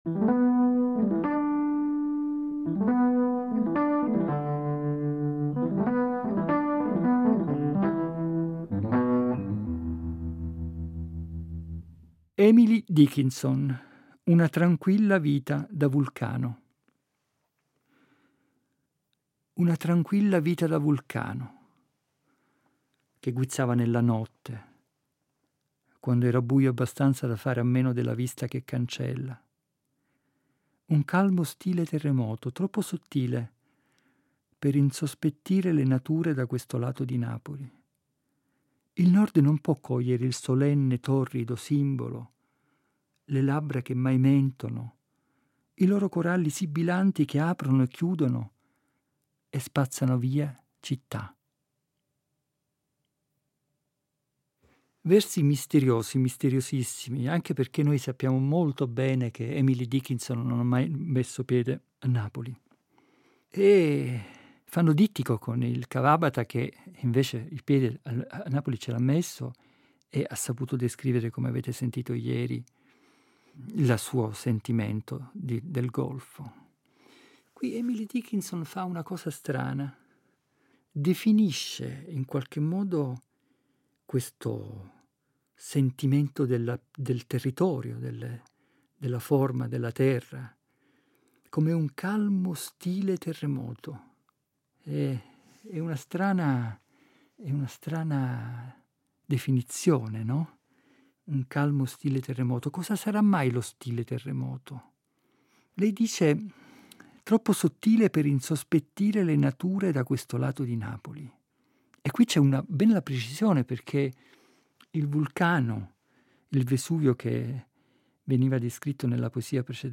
Ed è a partire da questo simbolo «delle equazioni casalinghe» che hanno caratterizzato il tempo del lockdown (una parte delle registrazioni è stata pensata e realizzata proprio fra le mura domestiche) che egli ci guida nella rigogliosa selva della parola poetica per «dare aria ai pensieri». Il Giappone come invenzione nel mondo nei suoi riflessi anche in poeti non giapponesi e giochi di specchi di paesaggi alla ricerca dell’intelletto d’amore sono le linee guida di questa nuova settimana.